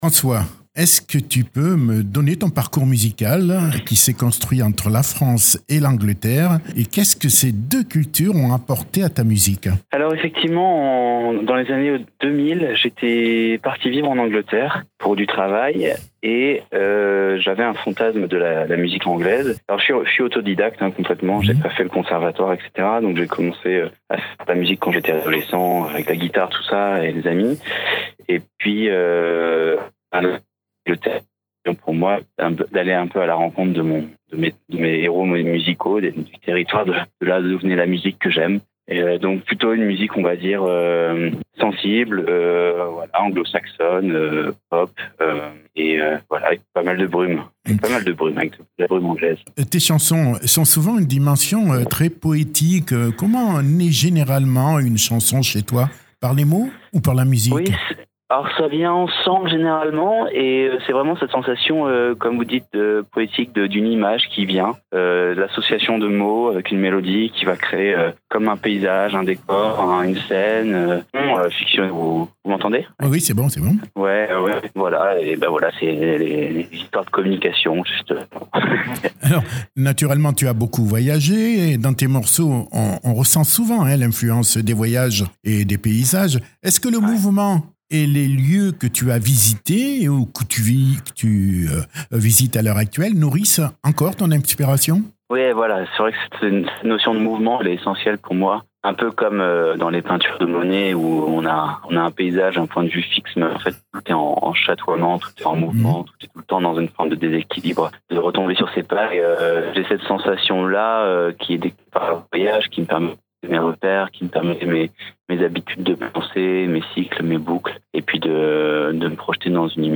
Le podcast de l’interview